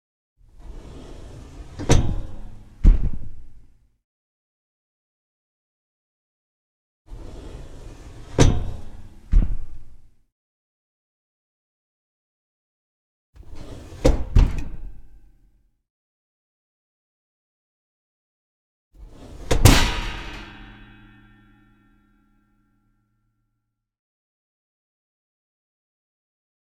household
Oven Door on Stove Close